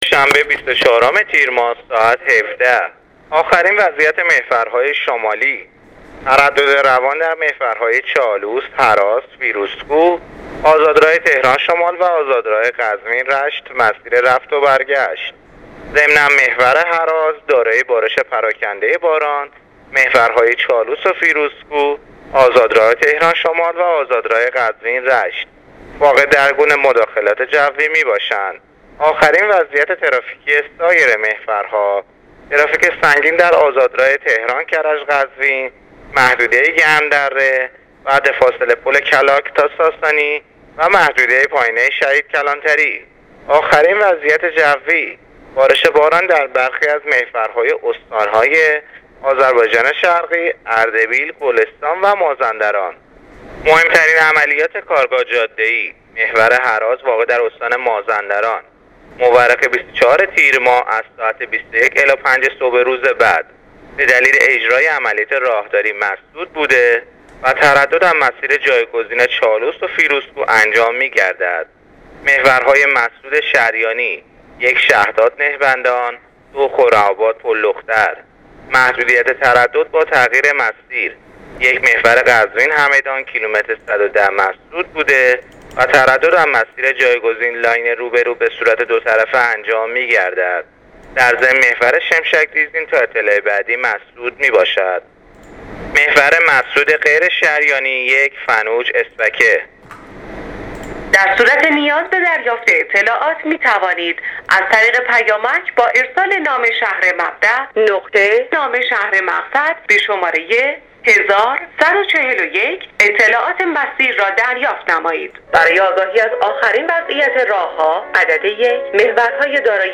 گزارش رادیواینترنتی از وضعیت ترافیکی جاده‌ها تا ساعت ۱۷ سه‌شنبه ۲۴ تیر